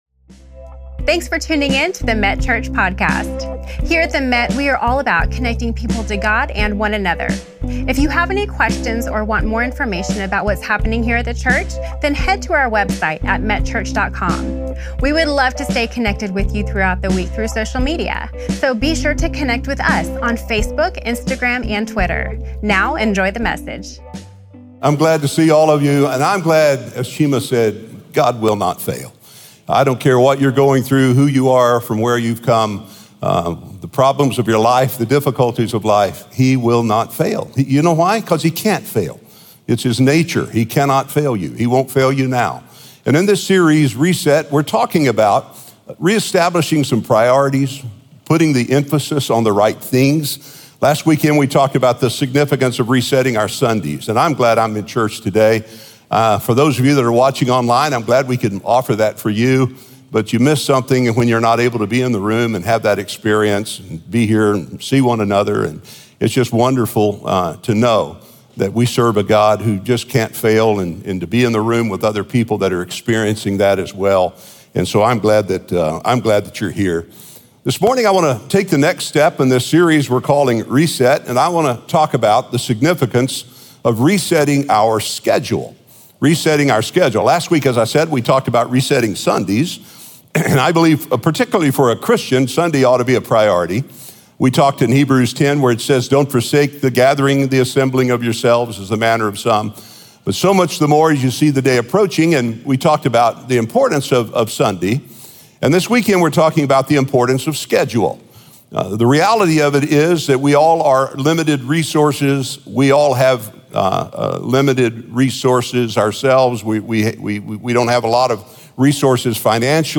Senior Pastor